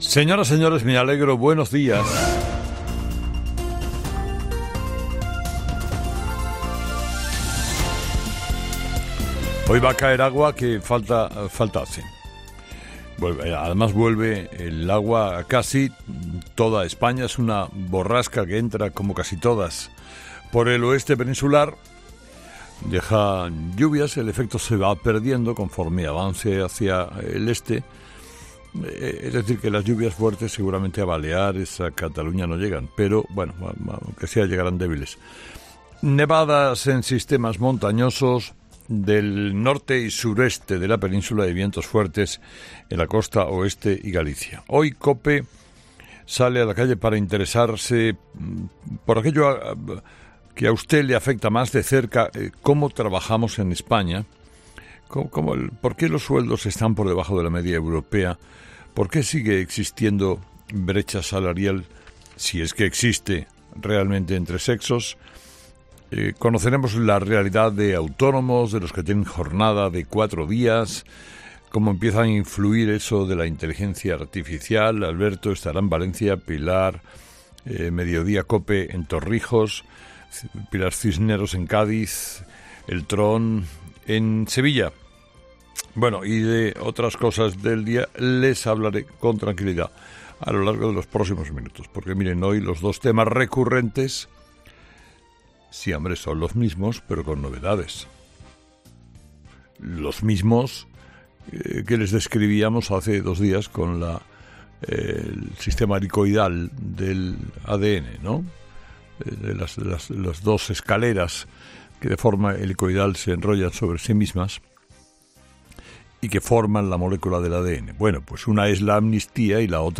AUDIO: Escucha el análisis de Carlos Herrera a las 06:00 en Herrera en COPE del jueves 7 de marzo